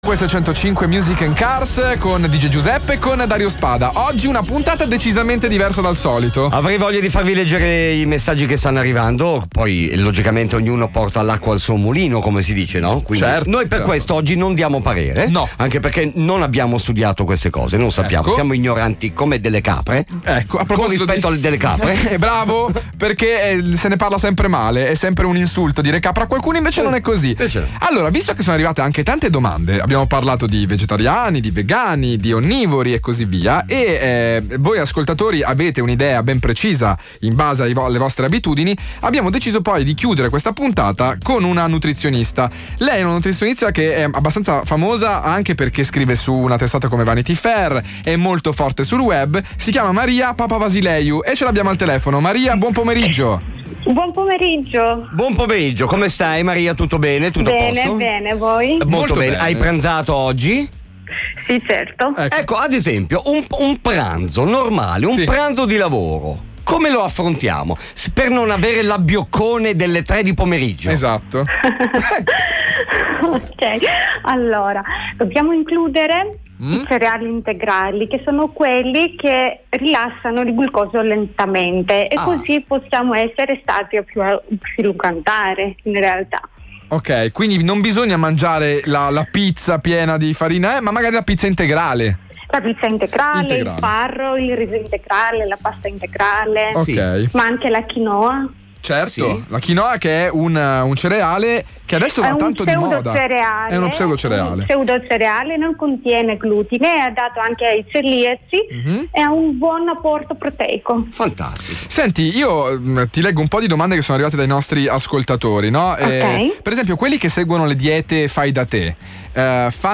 Intervento a Music & Cars